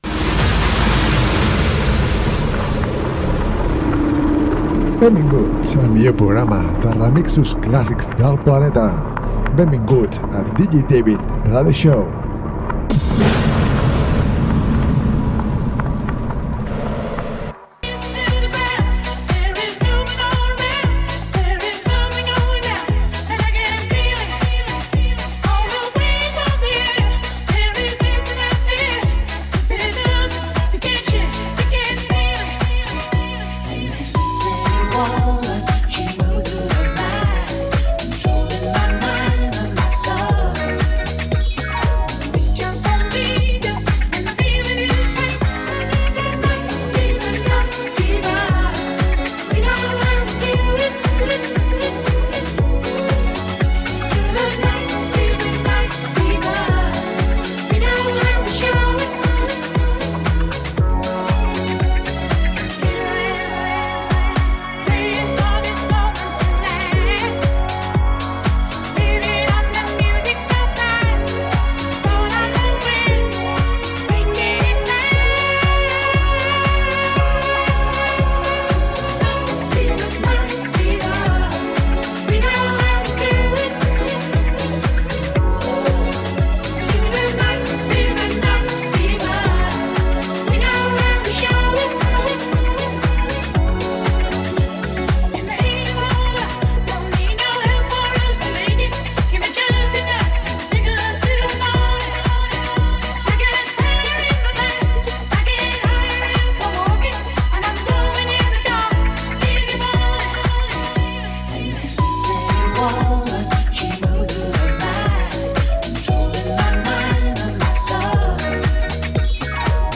programa de ràdio